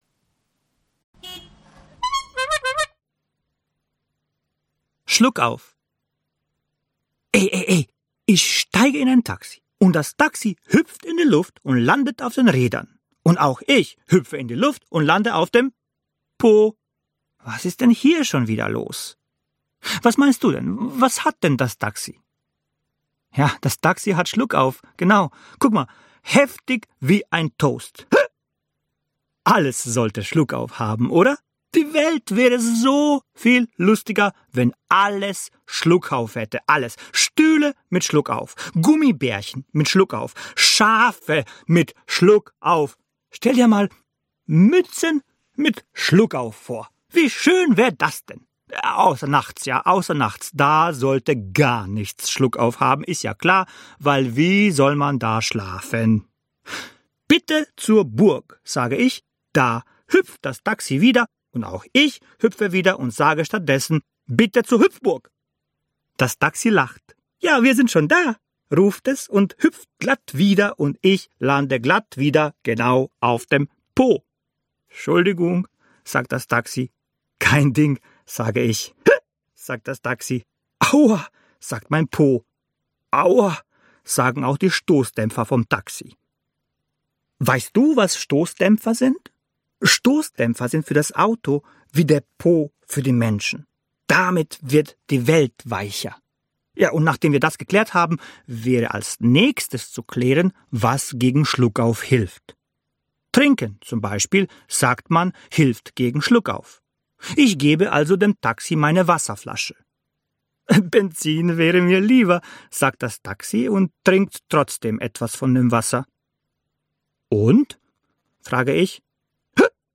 Hörprobe aus dem Hörbuch